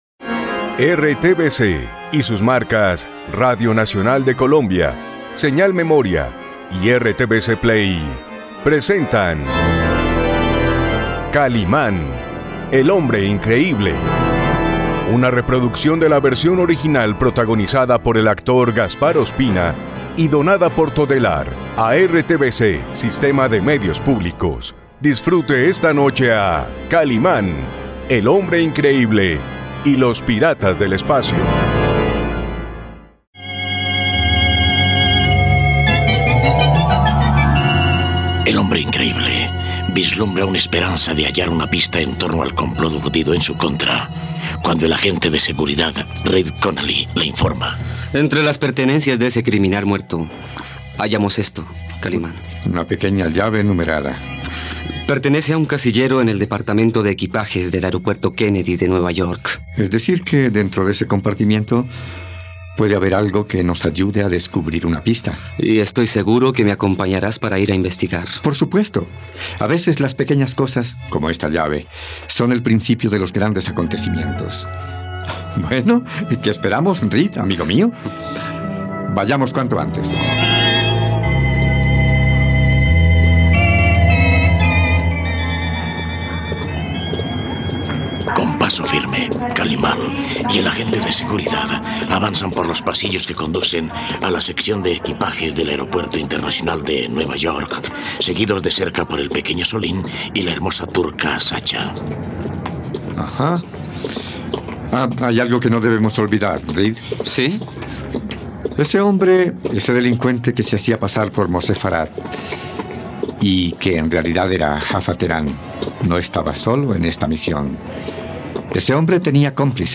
..Kalimán encuentra un maletín negro con una cuantiosa suma de dinero y el temporizador de una bomba. No te pierdas la radionovela de Kalimán y los piratas del espacio aquí, en RTVCPlay.